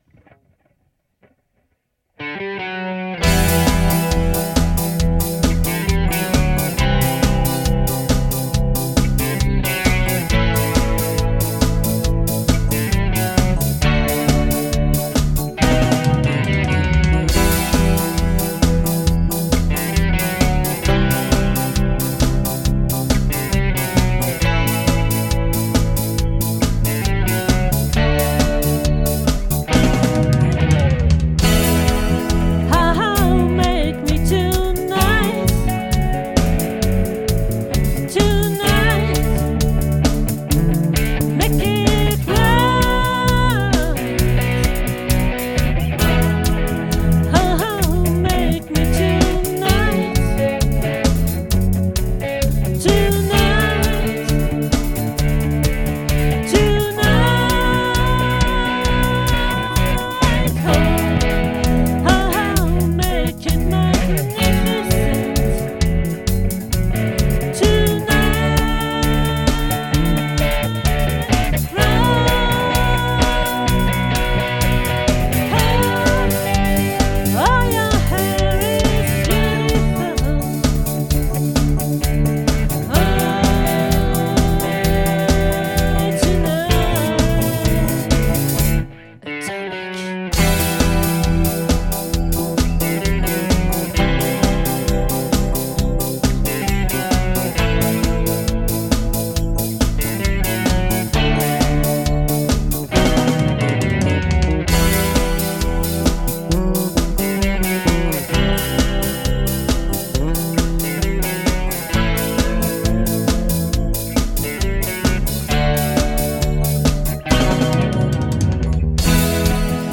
🏠 Accueil Repetitions Records_2022_10_12